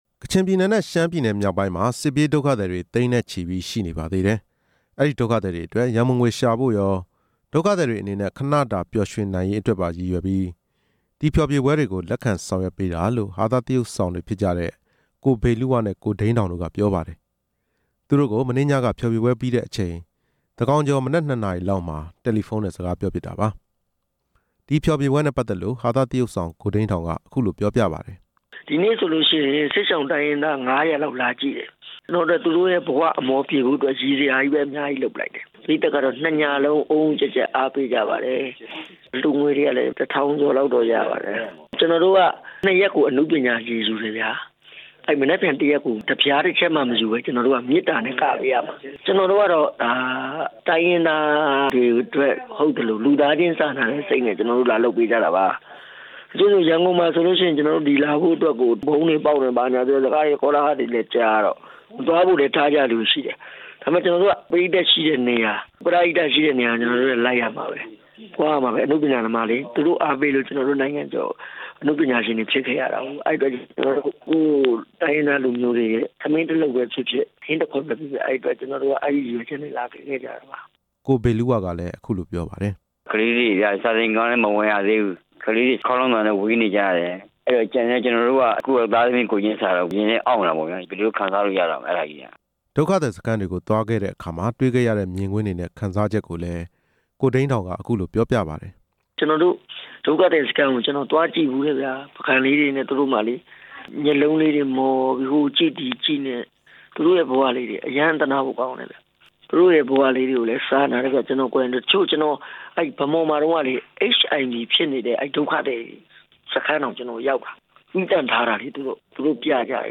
သူတို့ကို မနေ့ညက ဖျော်ဖြေပွဲပြီးတဲ့အချိန် သန်းခေါင်ကျော် မနက် ၂ နာရီလောက်မှာ တယ်လီဖုန်းနဲ့ စကားပြောဖြစ်တာပါ။ ဒီဖျော်ဖြေပွဲနဲ့ပတ်သက်လို့ ဟာသသရုပ်ဆောင် ကိုဒိန်းဒေါင်က အခုလိုပြောပြပါတယ်။